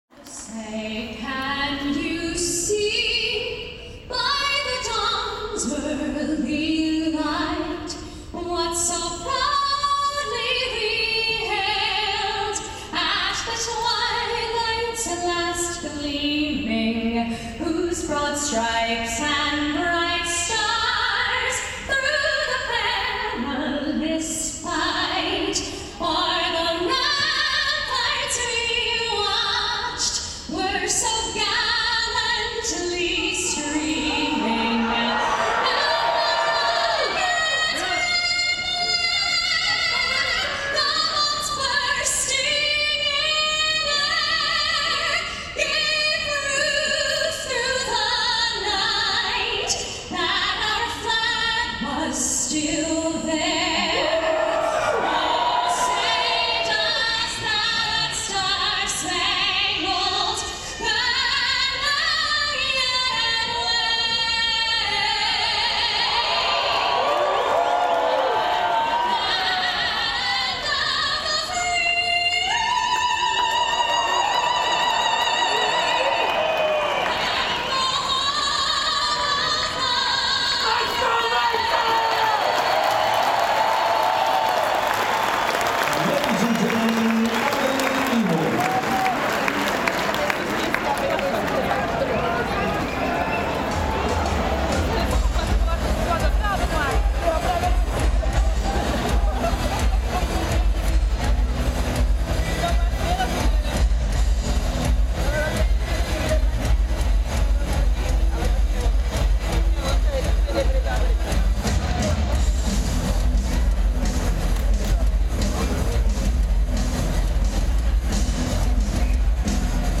Headliner Embed Embed code See more options Share Facebook X Subscribe Before every high-level (or indeed mid-level) sporting event in the USA, it's traditional for a singer to belt out The Star-Spangled Banner. Here, at a pre-season ice hockey friendly at the famous Madison Square Garden, we listen to the American national anthem before the action gets under way.